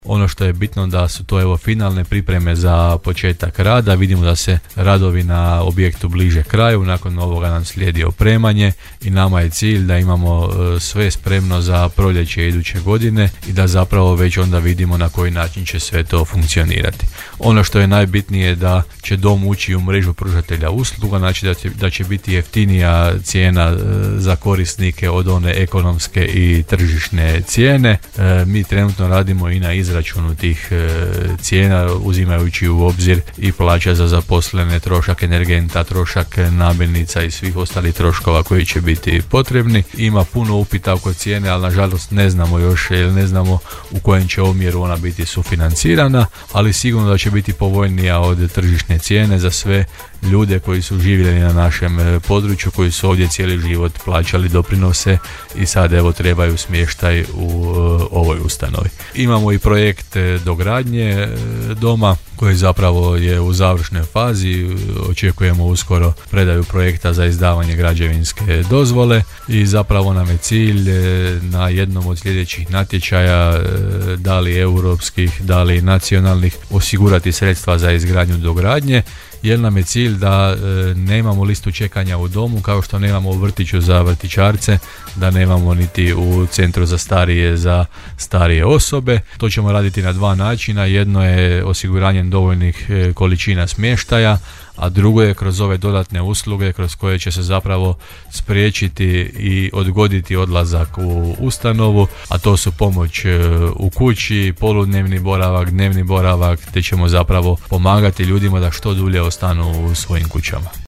-kazao je u emisiji Gradske teme gradonačelnik Hrvoje Janči.